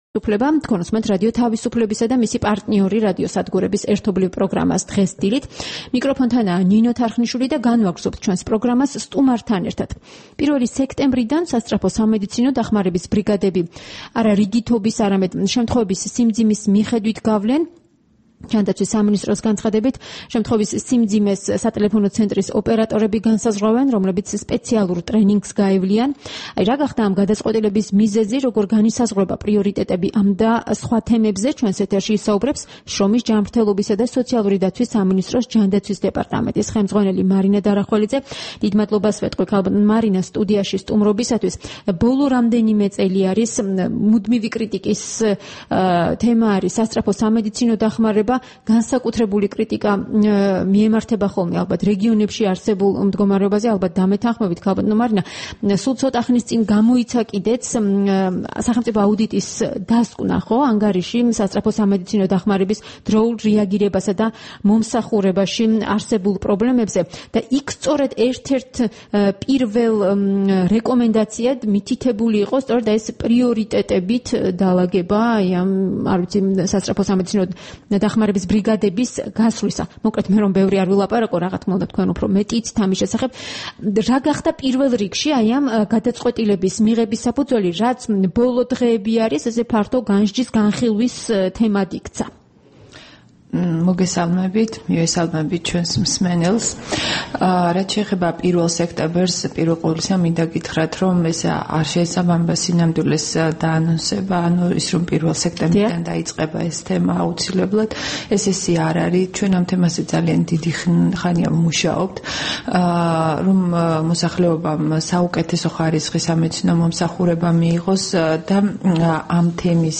სტუმრად ჩვენს ეთერში: მარინა დარახველიძე
საუბარი მარინა დარახველიძესთან